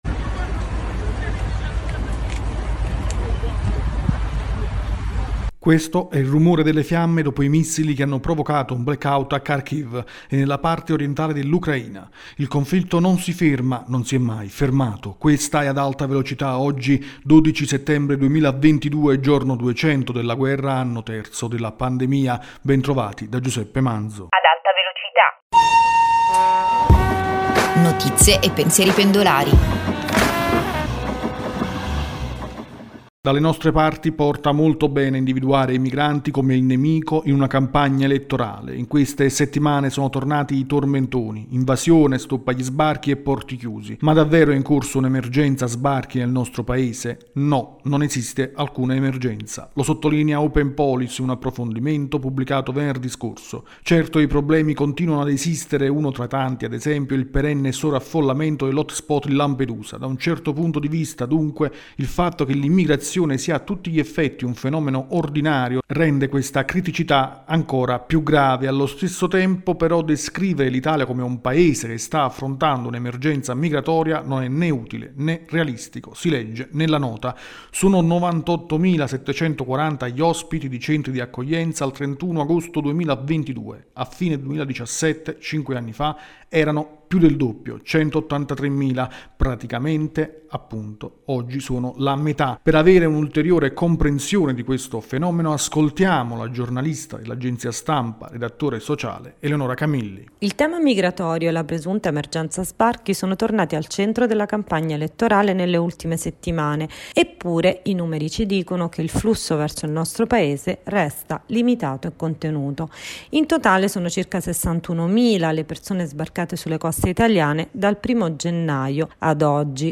Questo è il rumore delle fiamme dopo i missili che hanno provocato un blackout a Kharkhiv e nella parte orientale dell’Ucraina: il conflitto non si ferma, non si è mai fermato.